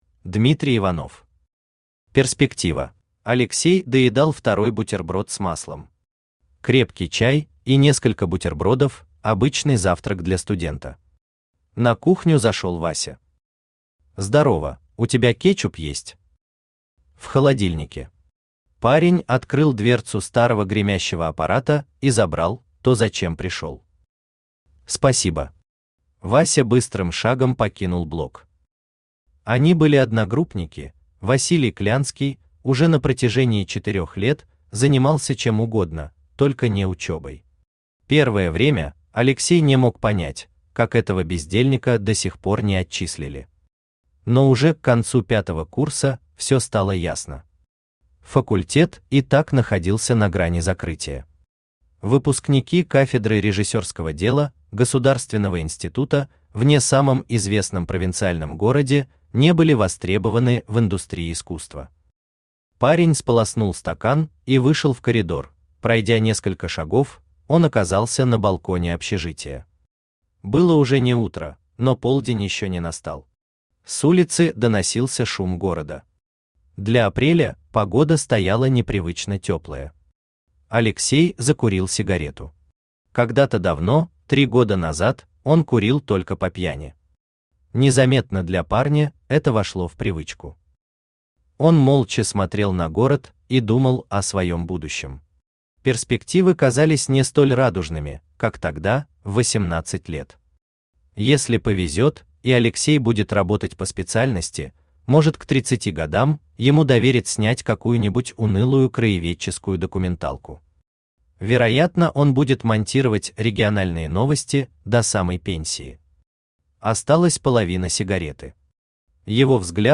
Аудиокнига Перспектива | Библиотека аудиокниг
Aудиокнига Перспектива Автор Дмитрий Иванов Читает аудиокнигу Авточтец ЛитРес.